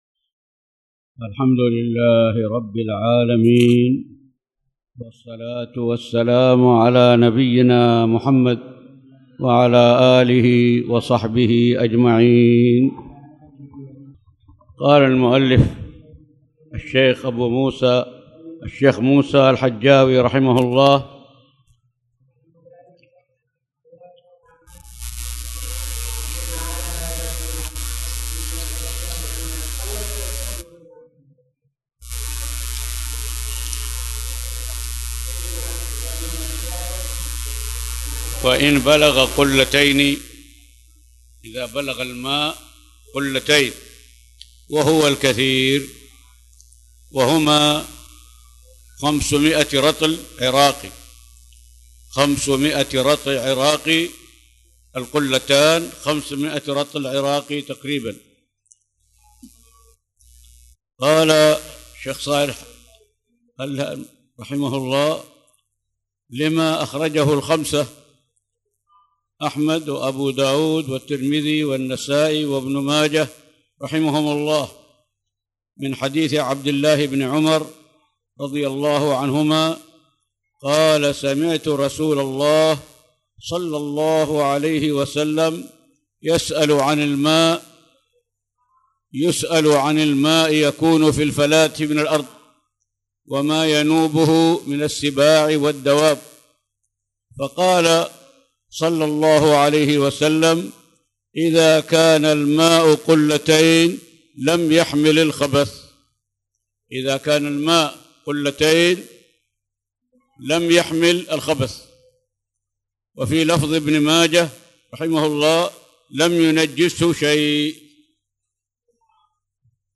تاريخ النشر ١٢ ربيع الثاني ١٤٣٨ هـ المكان: المسجد الحرام الشيخ